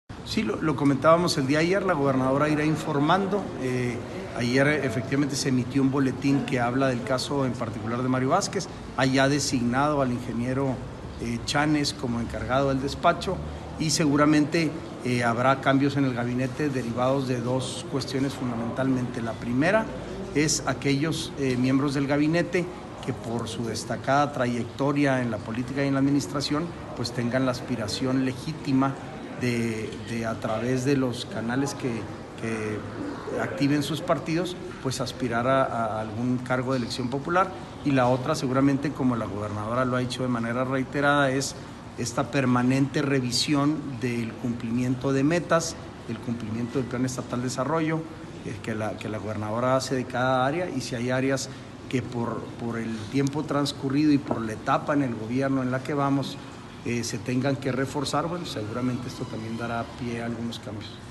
AUDIO: SANTIAGO DE LA PEÑA, TITULAR DE LA SECRETARÍA GENERAL DE GOBIERNO (SGG)